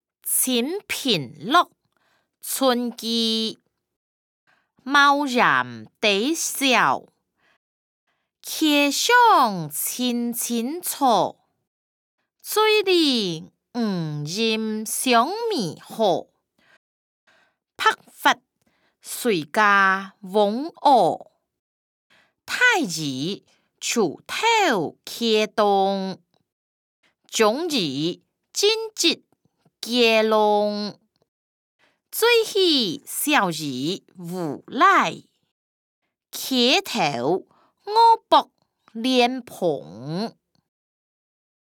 詞、曲-清平樂：村居 音檔(大埔腔)